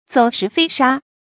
走石飛沙 注音： ㄗㄡˇ ㄕㄧˊ ㄈㄟ ㄕㄚ 讀音讀法： 意思解釋： 沙土飛揚，石塊滾動。形容風勢狂暴。